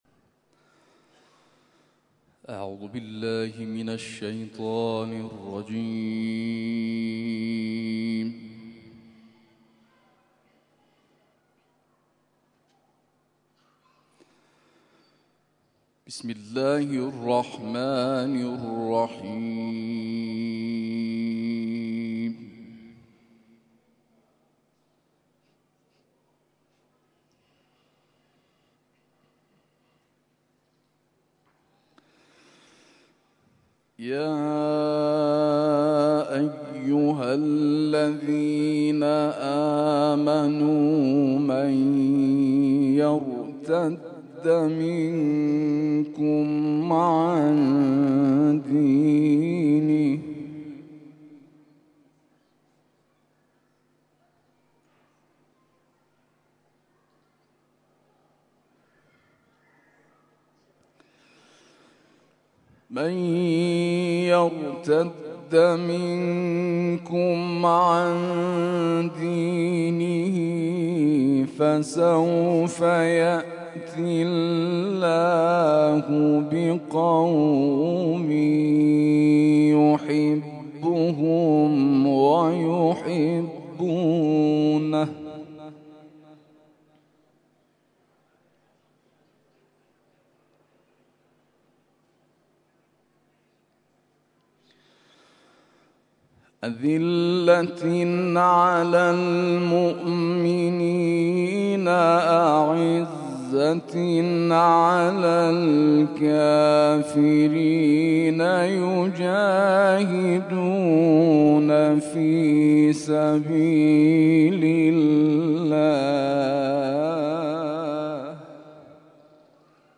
تلاوت صبح